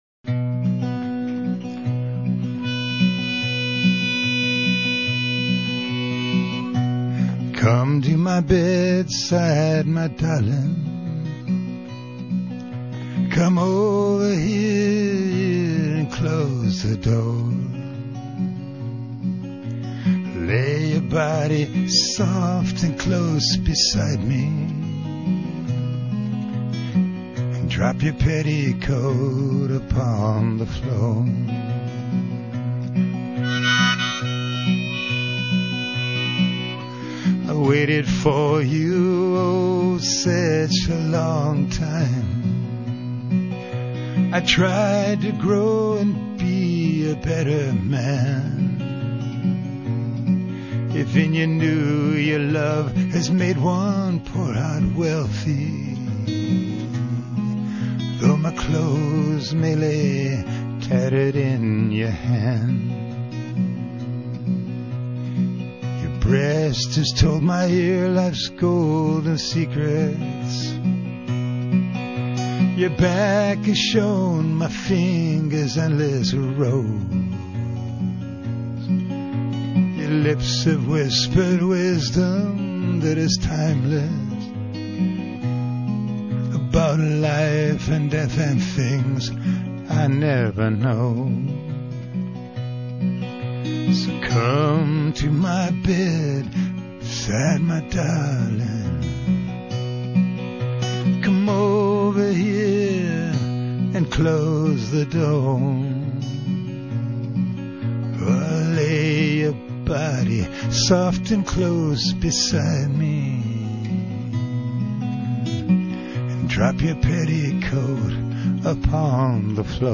live songs (from radio)